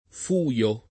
vai all'elenco alfabetico delle voci ingrandisci il carattere 100% rimpicciolisci il carattere stampa invia tramite posta elettronica codividi su Facebook fuio [ f 2L o ] agg. — voce ant. per «ladro; malvagio»; attestata però quasi solo nel femm. fuia